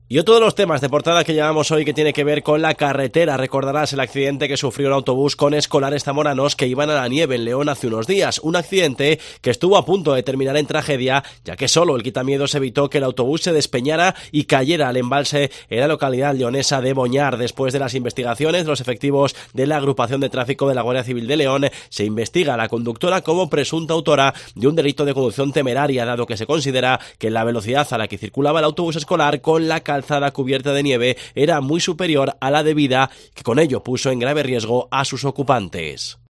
Crónica accidente autobús escolar de zamoranos en León